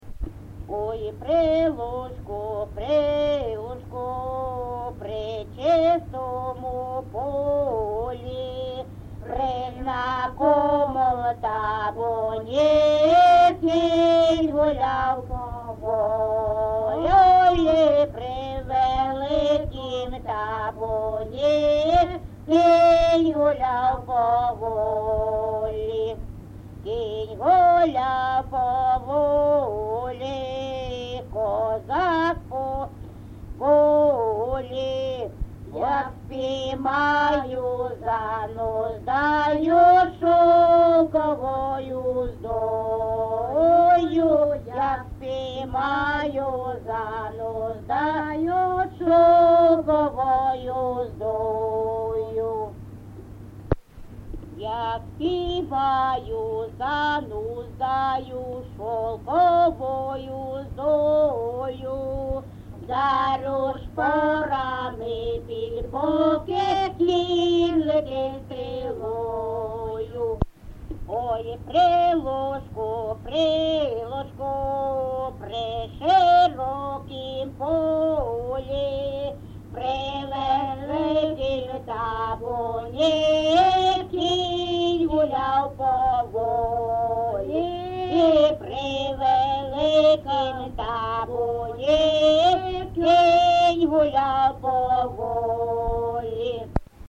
ЖанрПісні з особистого та родинного життя, Козацькі
Місце записус. Гнилиця, Сумський район, Сумська обл., Україна, Слобожанщина